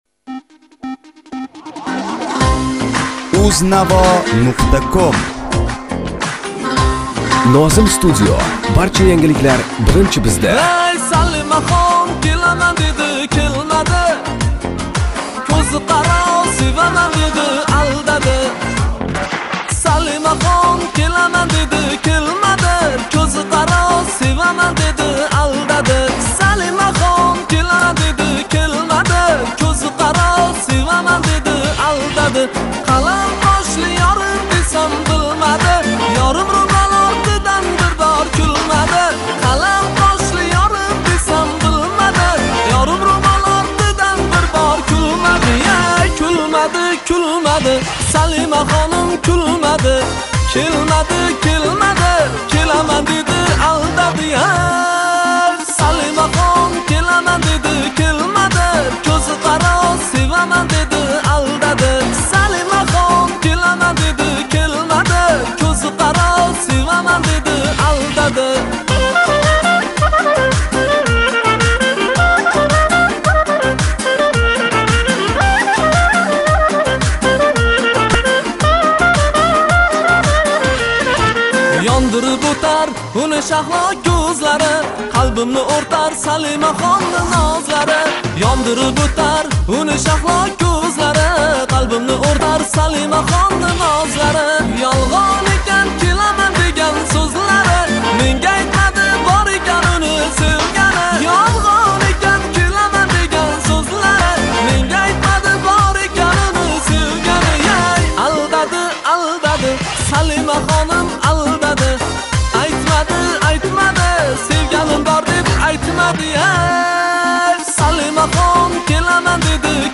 minus